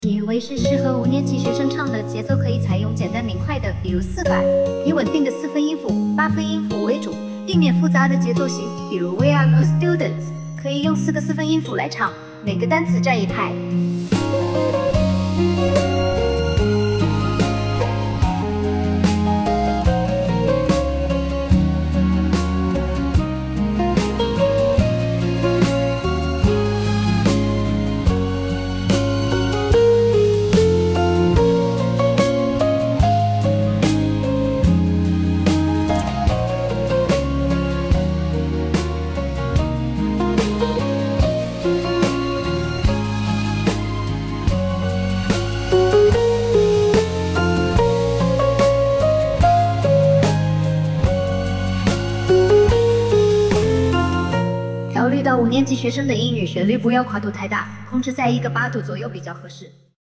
人工智能生成式歌曲